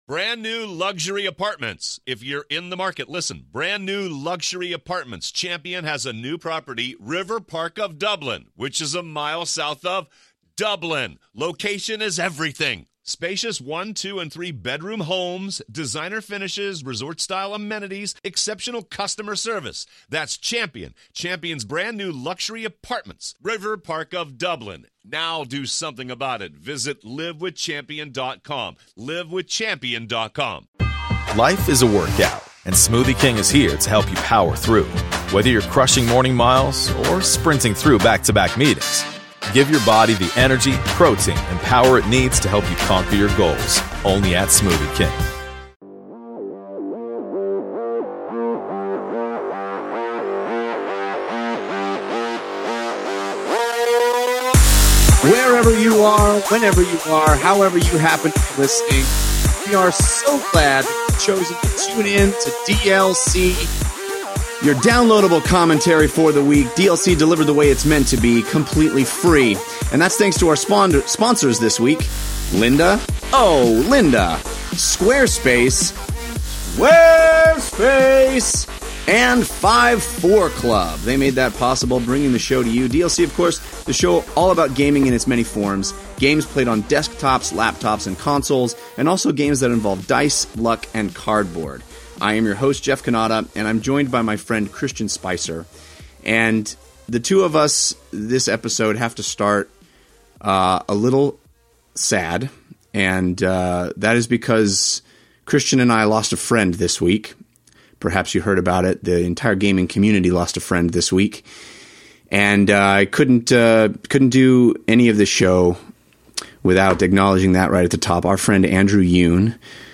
All that, plus YOUR phone calls.